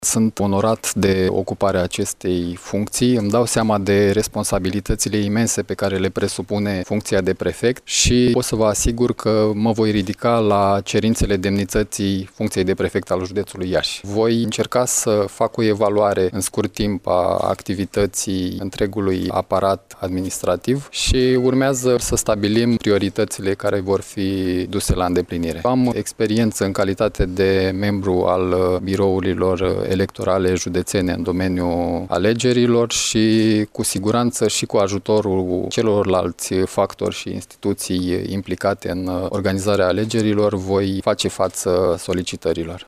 Marian Grigoraş, noul prefect de Iaşi, a depus astăzi jurământul cu ocazia învestirii în funcţie.